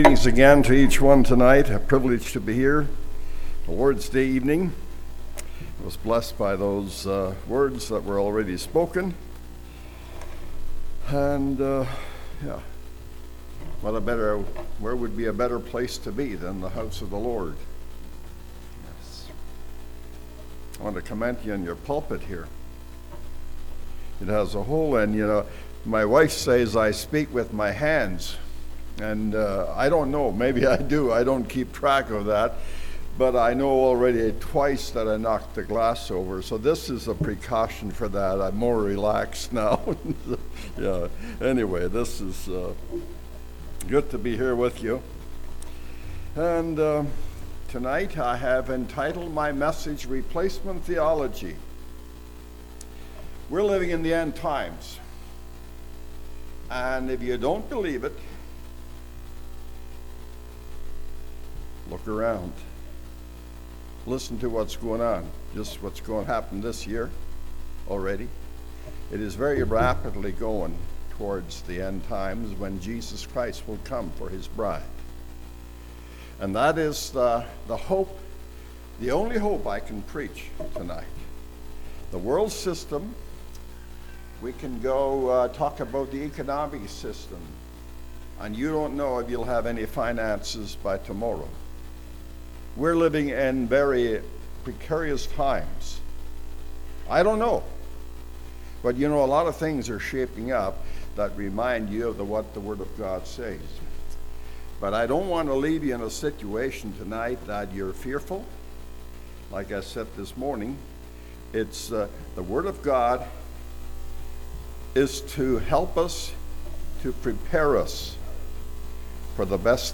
Congregation: Great Lakes